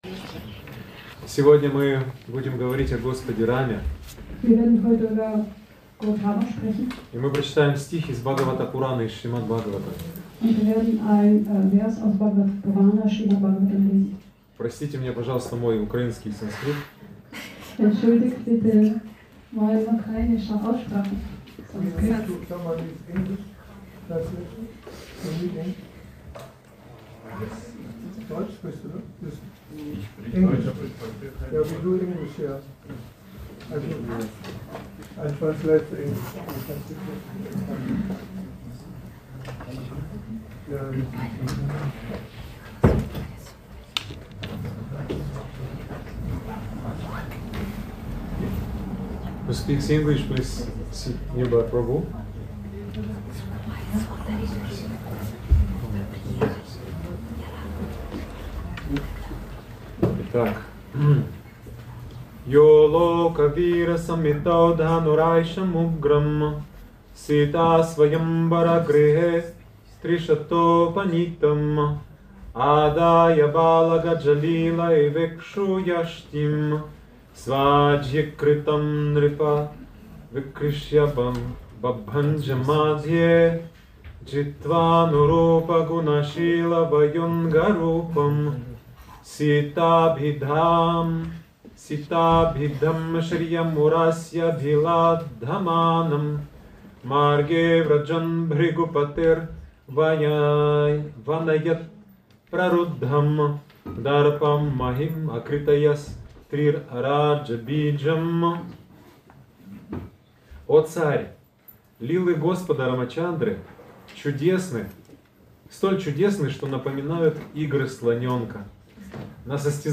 Rama Navami Feierlichkeiten – Vortrag